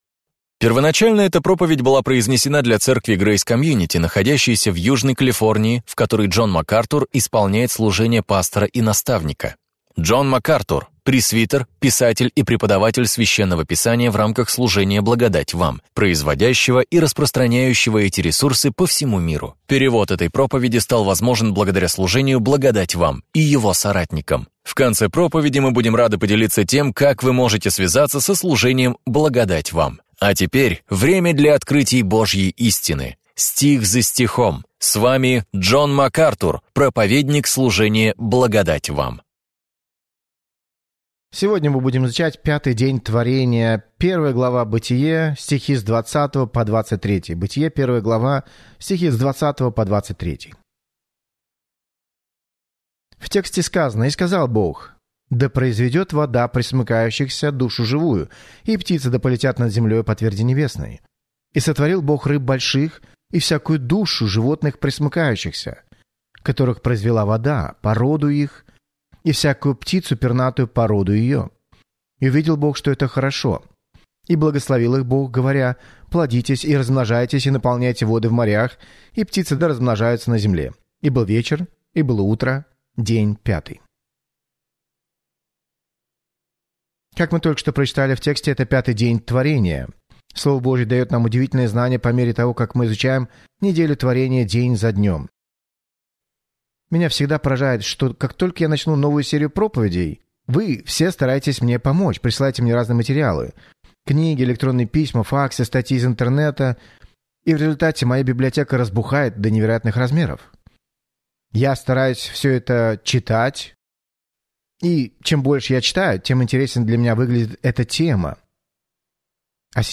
Проповеди МакАртура